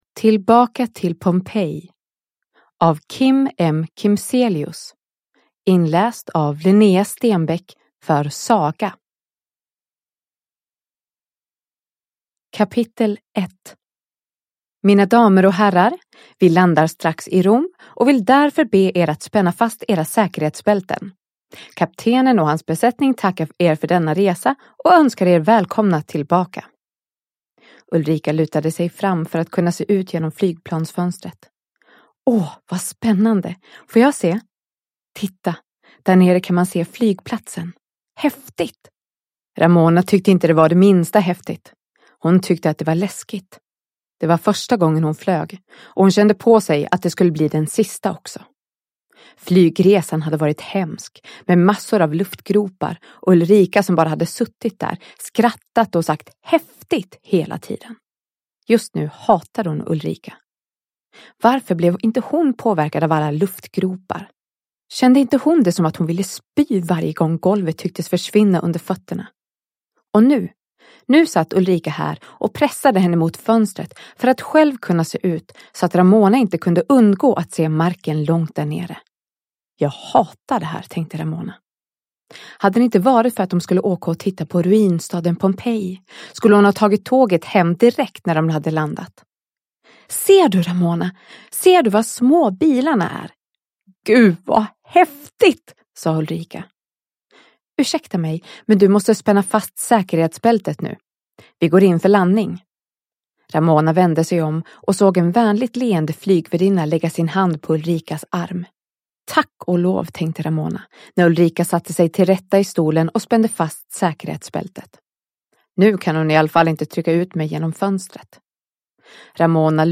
Tillbaka till Pompeji – Ljudbok – Laddas ner